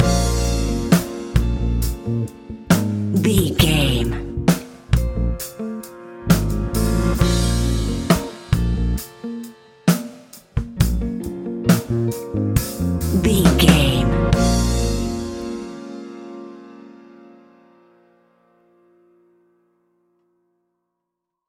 Epic / Action
Fast paced
In-crescendo
Uplifting
Ionian/Major
A♯
hip hop